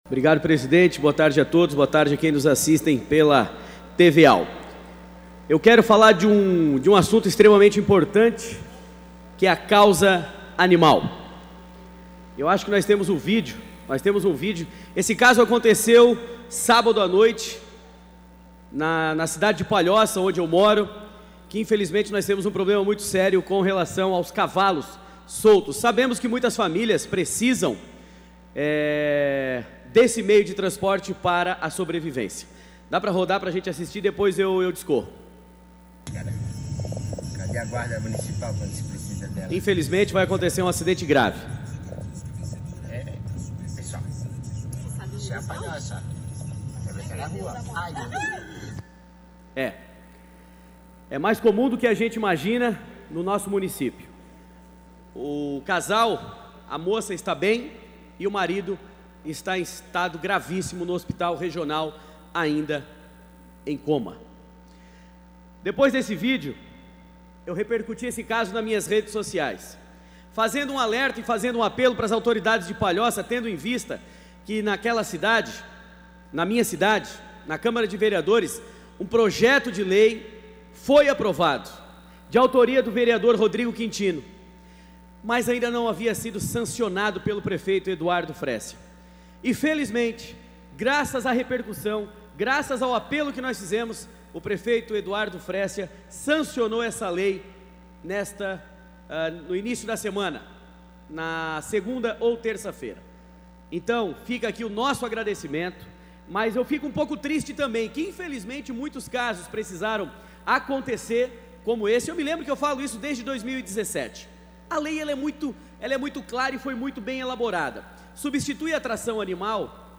Pronunciamentos da sessão ordinária desta terça-feira (7)